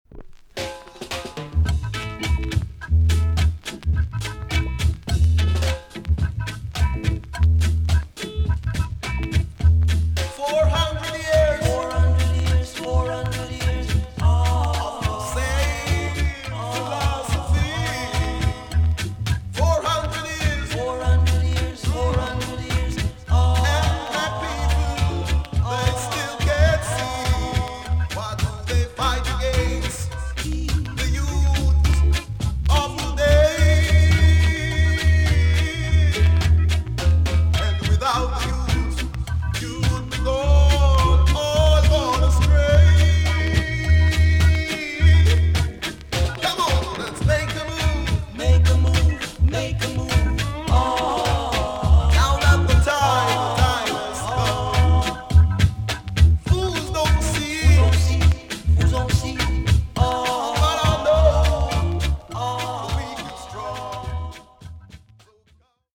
TOP >LP >VINTAGE , OLDIES , REGGAE
B.SIDE VG+ 少し軽いチリノイズが入ります。